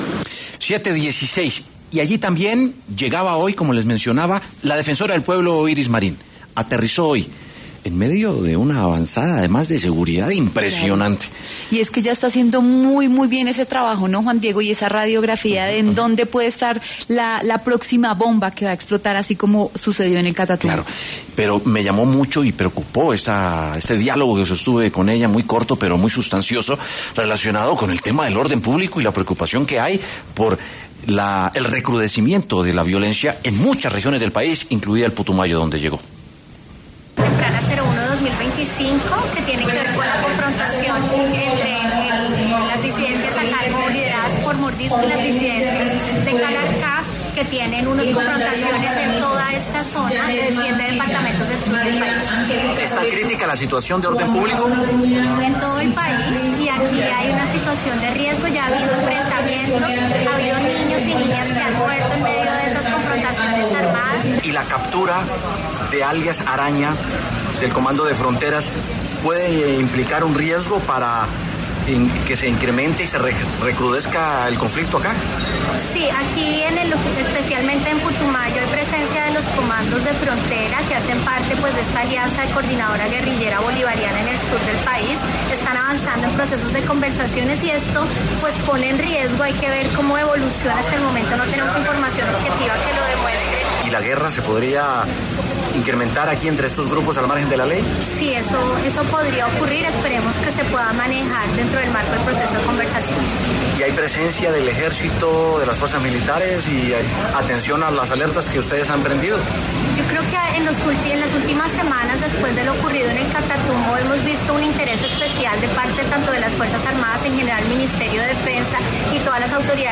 Por esta razón, en los micrófonos de W Sin Carreta habló la misma defensora del Pueblo, Iris Marín, quien se refirió al tema.